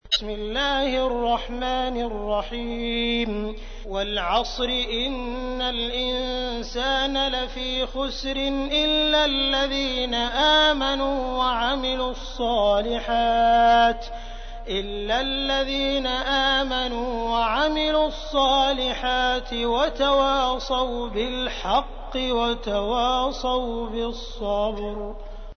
تحميل : 103. سورة العصر / القارئ عبد الرحمن السديس / القرآن الكريم / موقع يا حسين